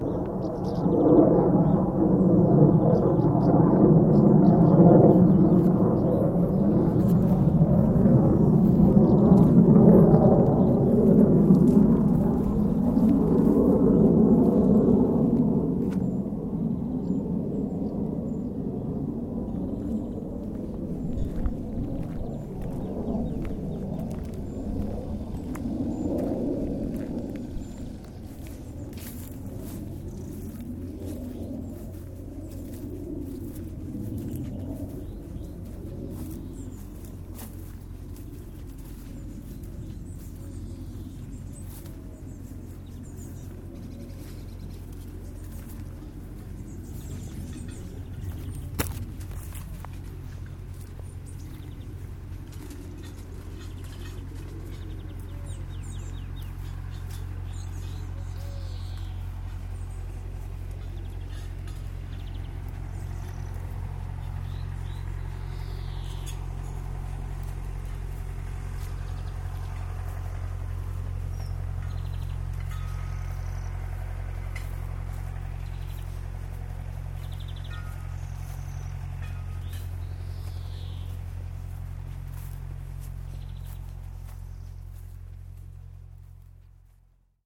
Taking a walk in June recording sounds around the village